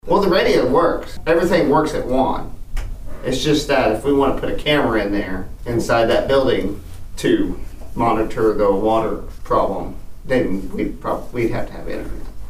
District Two Commissioner Brandon Wesson said there's one final step before the full maintenance will be complete.